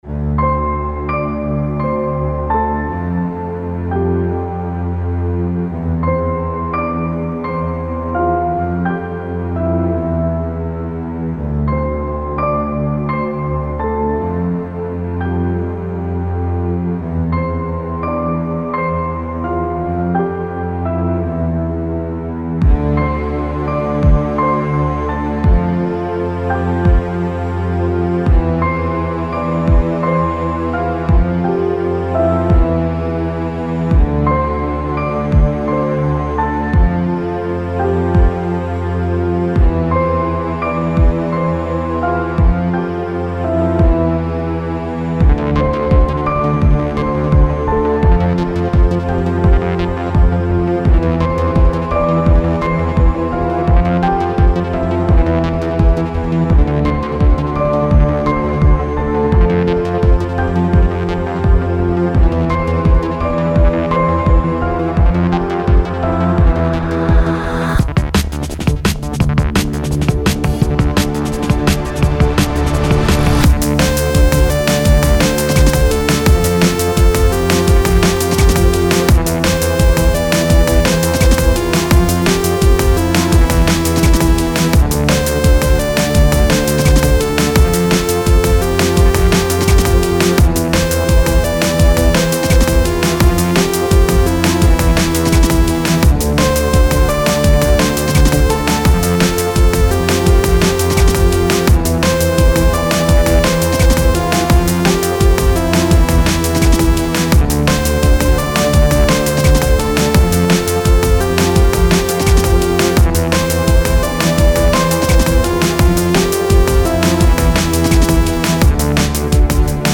because happy DnB~